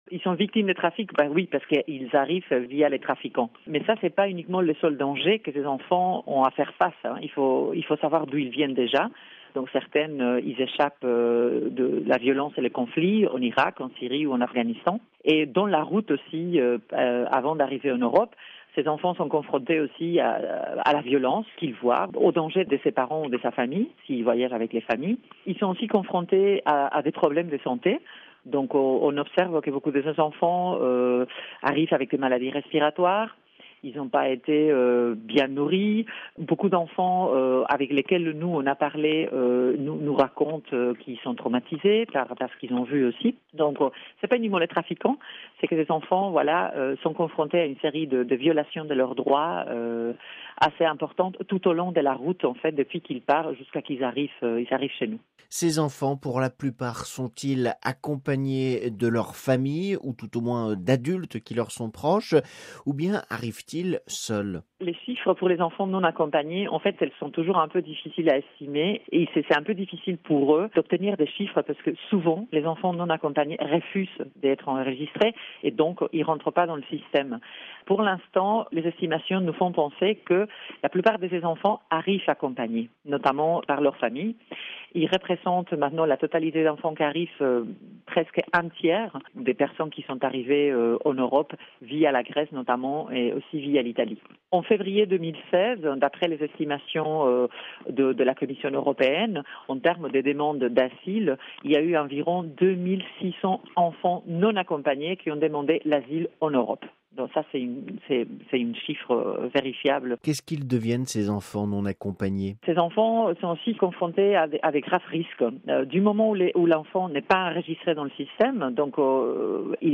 RV) Entretien - « Enfants, levez-vous ! » : c’est le thème de la XXe journée des enfants victimes de la violence, de l’exploitation et de l’indifférence contre la pédophilie célébrée en Italie et dont l’association Meter est à l’origine.